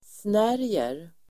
Uttal: [sn'är:jer]